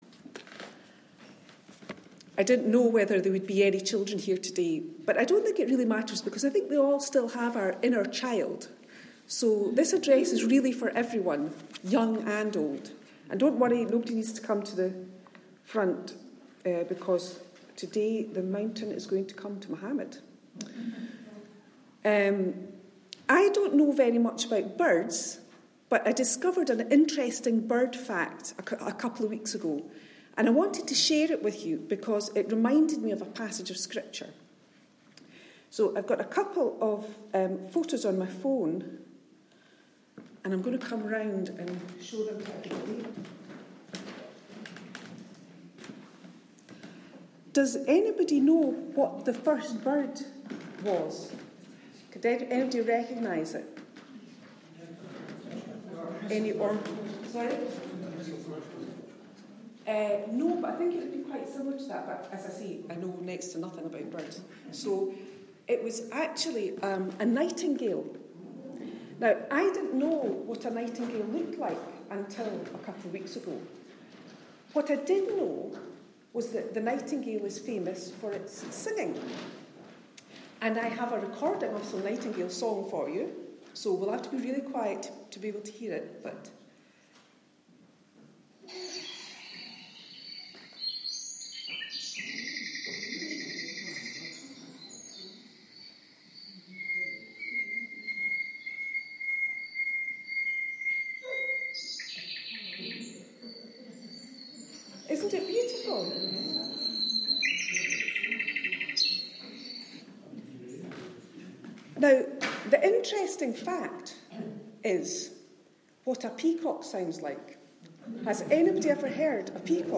The audio recording of today’s service comprises the Children’s Address and main message which, together, explore God’s creation, with an encouragement to trust in God’s plans for us and for all His creatures.